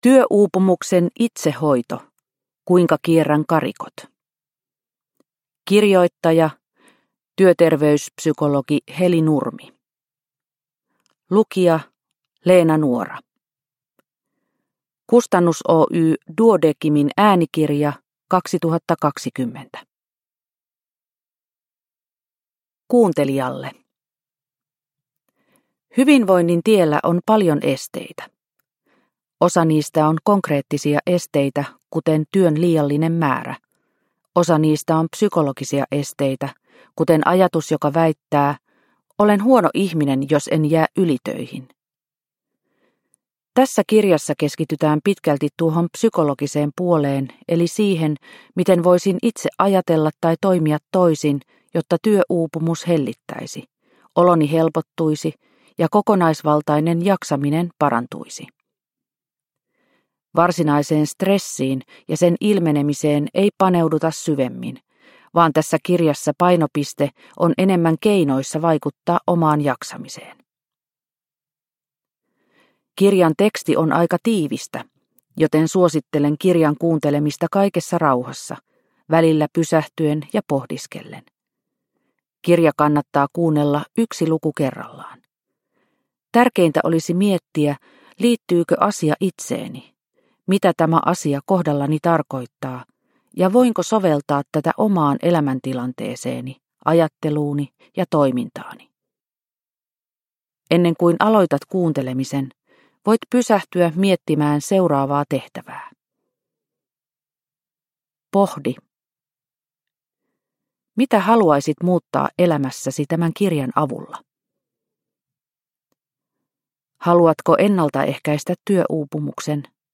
Työuupumuksen itsehoito – Ljudbok – Laddas ner